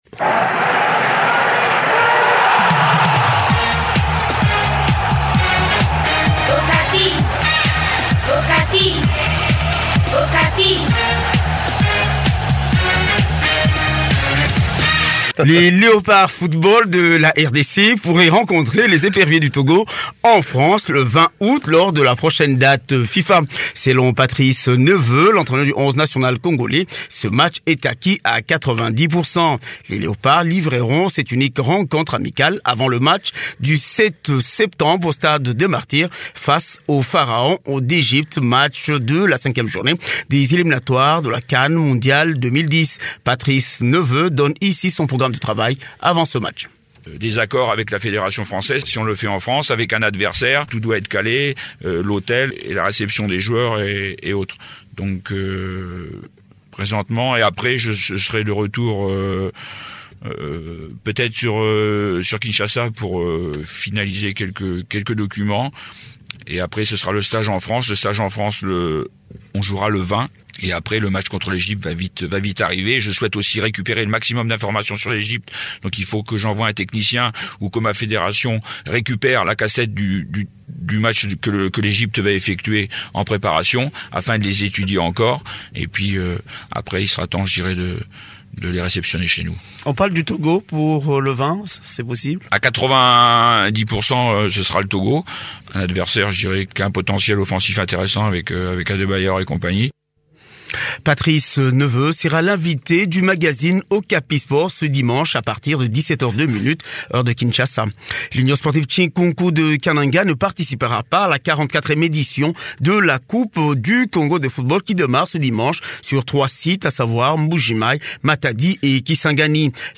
Il est au téléphone